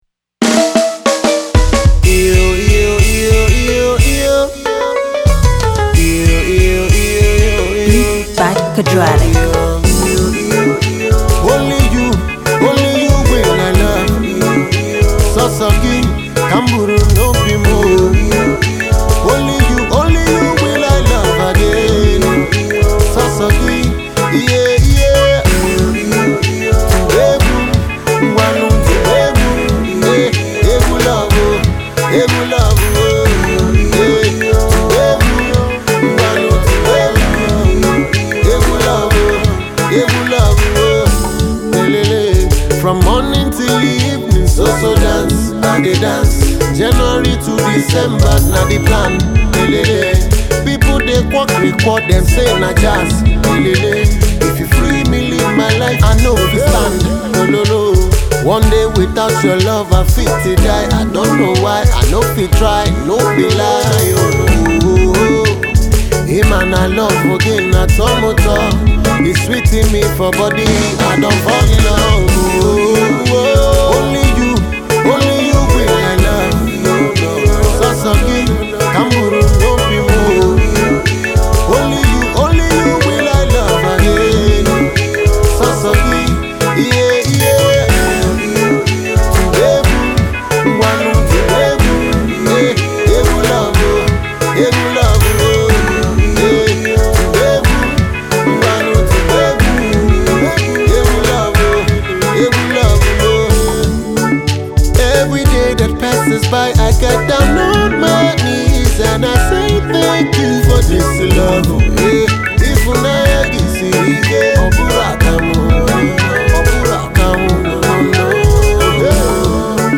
This Freshly cultivated Highlife-love song